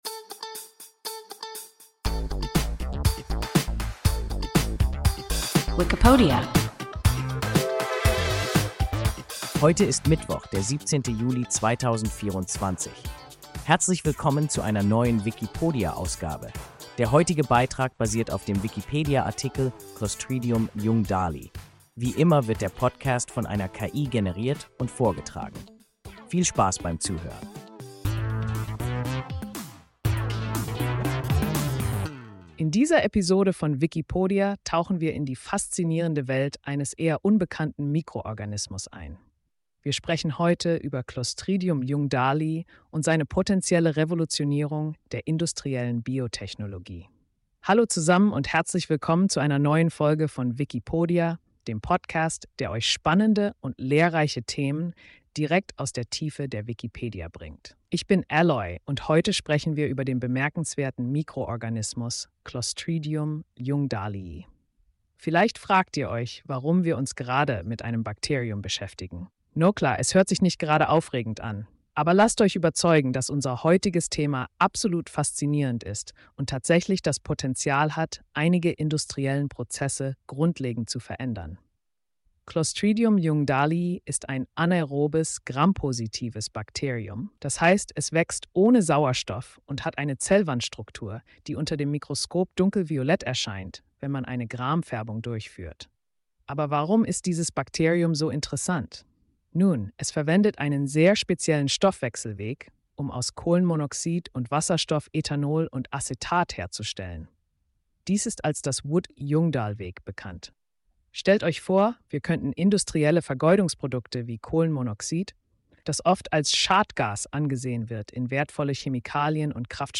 Clostridium ljungdahlii – WIKIPODIA – ein KI Podcast